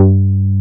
R MOOG G3P.wav